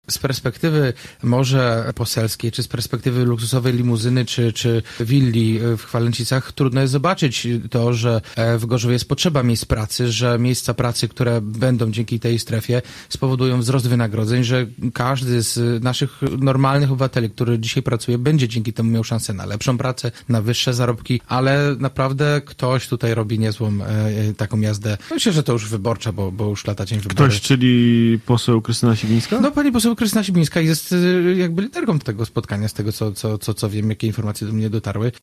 Jacek Wójcicki na antenie Radia Gorzów stwierdził, że poseł Sibińska rozpoczęła kampanię wyborczą: